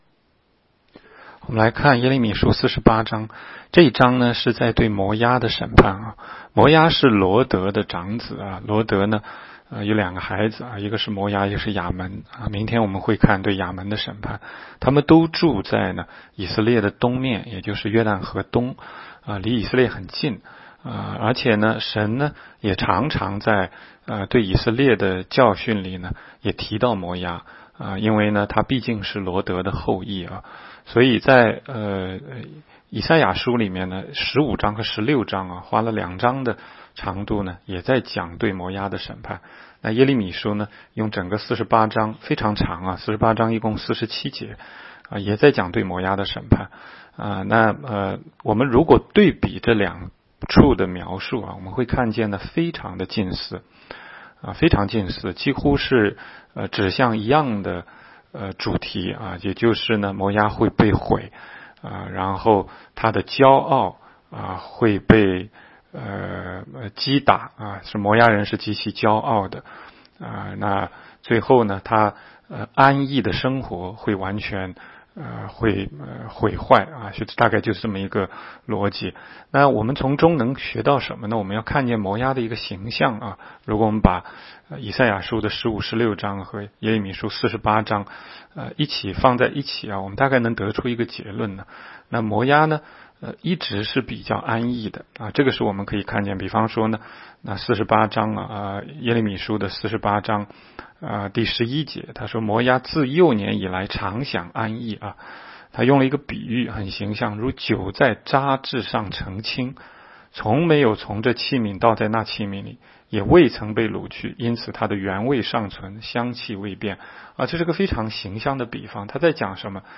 16街讲道录音 - 每日读经 -《耶利米书》48章